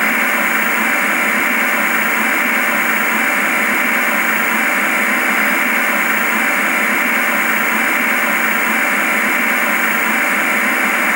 TVstatic.wav